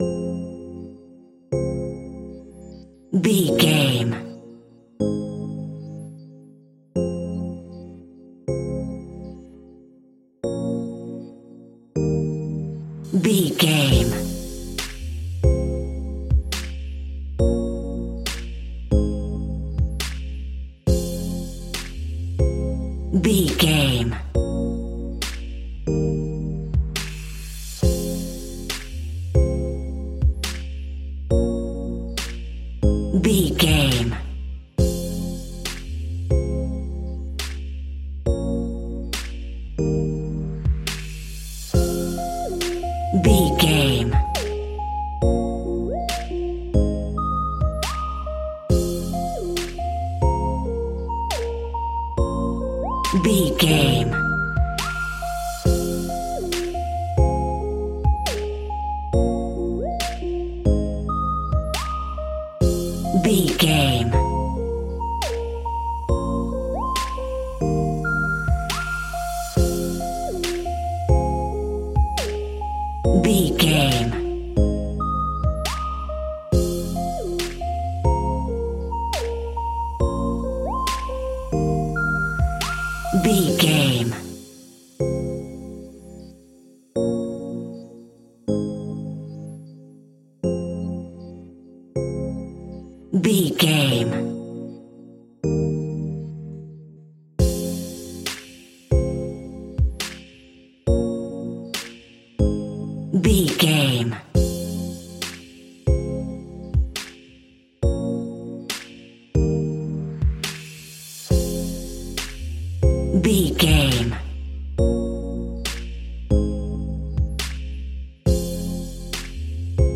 Aeolian/Minor
Slow
hip hop
instrumentals
chilled
laid back
groove
hip hop drums
hip hop synths
piano
hip hop pads